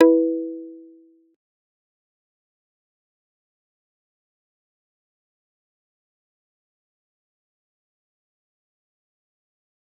G_Kalimba-E4-pp.wav